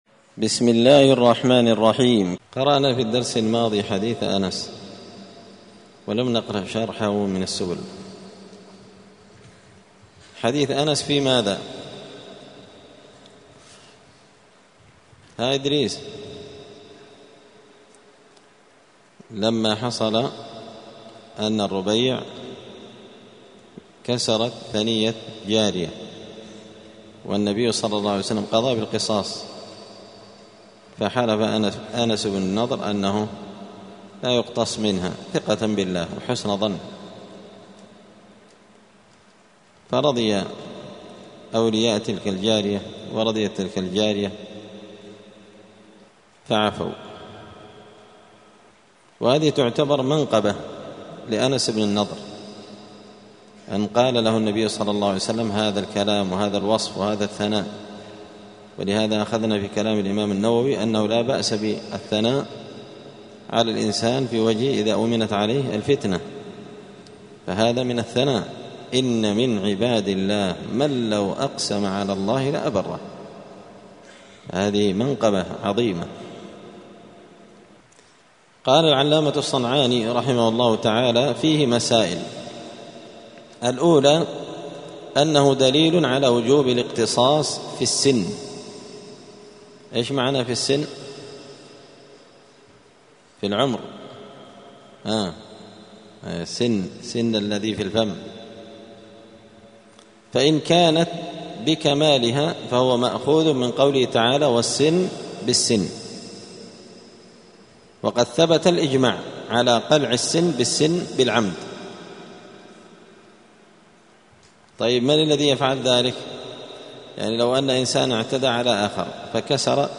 الثلاثاء 10 محرم 1446 هــــ | الدروس، سبل السلام شرح بلوغ المرام لابن الأمير الصنعاني، كتاب الجنايات | شارك بتعليقك | 32 المشاهدات
دار الحديث السلفية بمسجد الفرقان بقشن المهرة اليمن